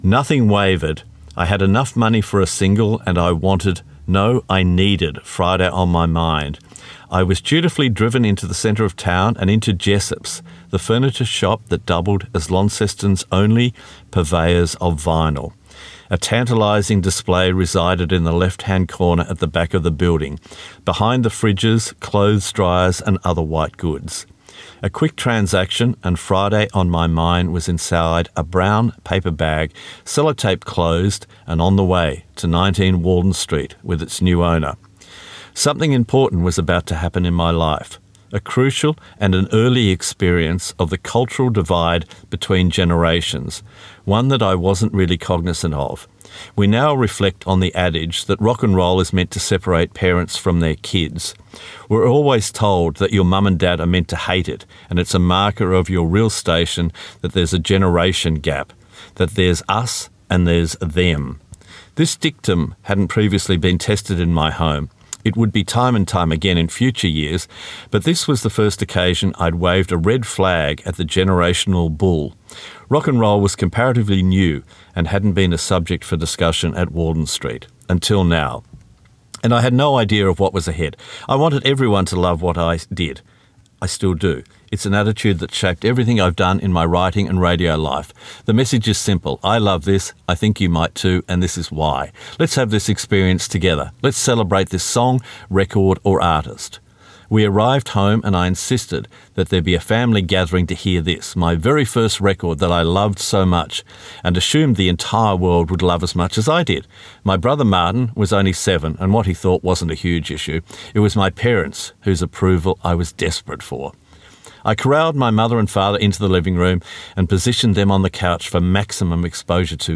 Recorded at Bellingen Readers and Writers Festival 2024
stuart-coupe-read.mp3